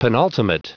Prononciation du mot penultimate en anglais (fichier audio)